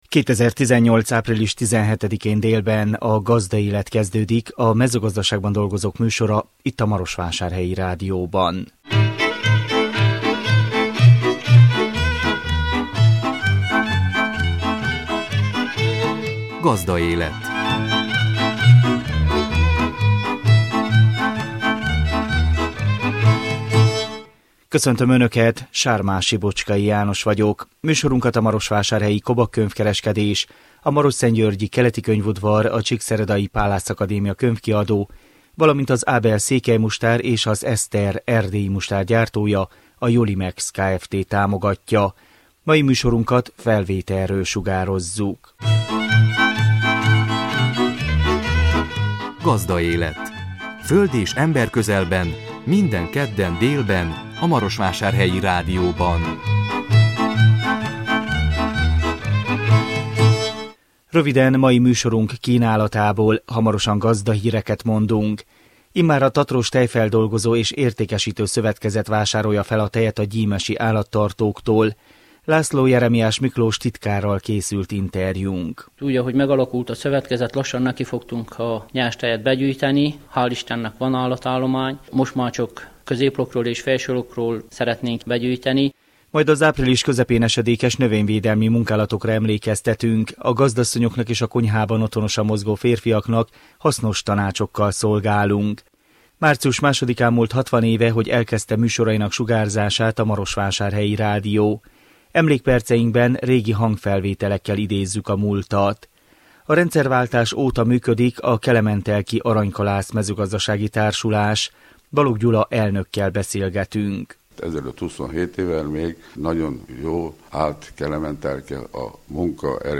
Emlékperceinkben régi hangfelvételekkel idézzük a múltat.